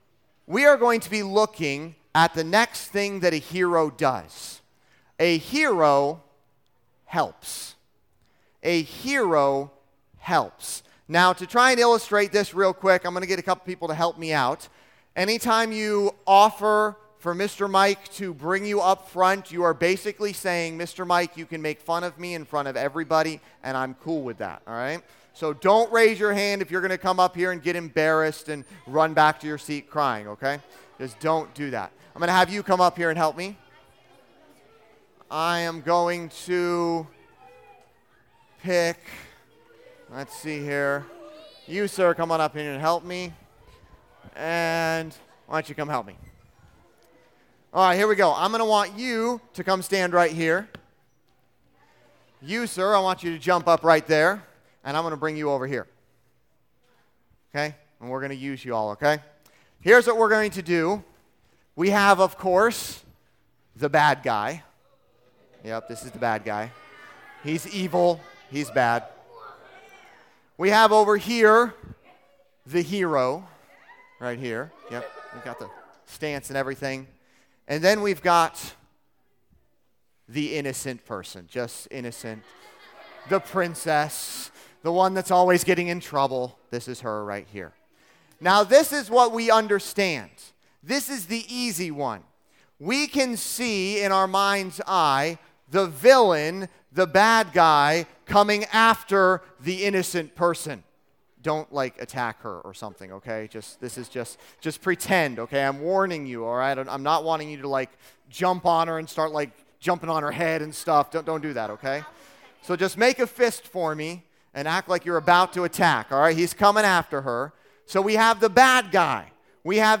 Listen to Message
Service Type: Junior Camp